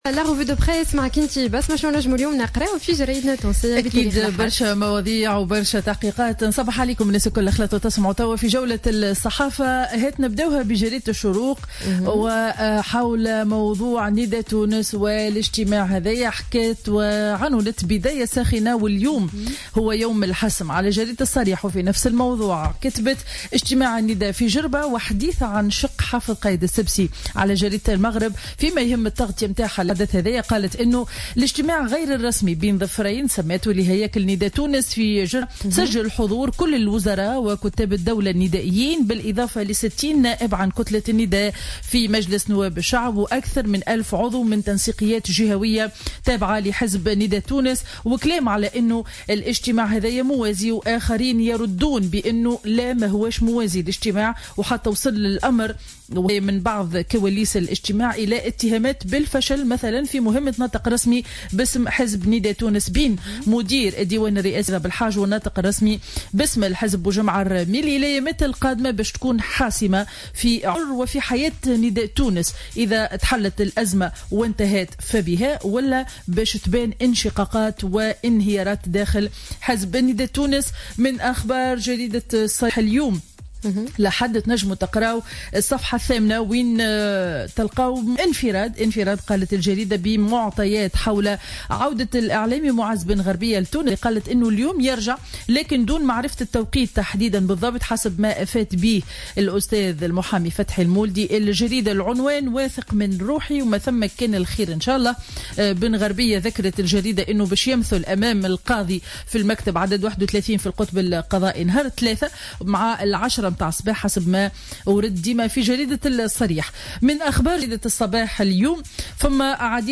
Revue de presse du dimanche 18 octobre 2015